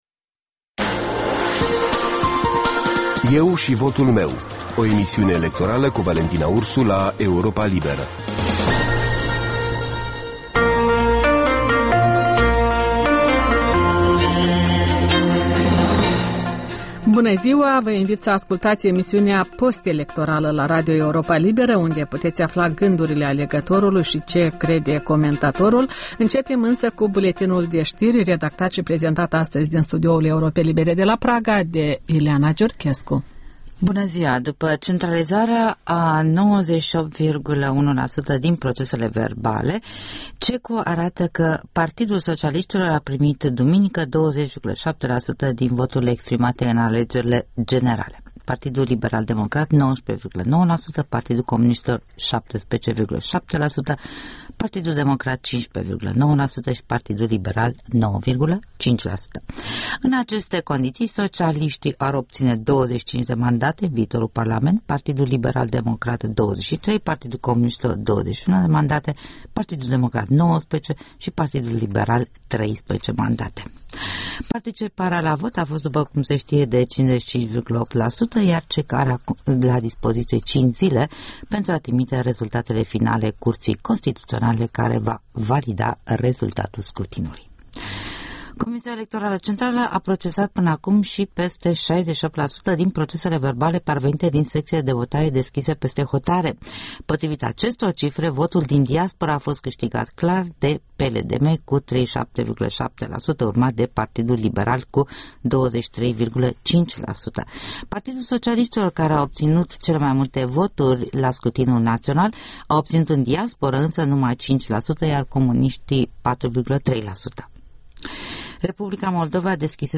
O emisiune electorală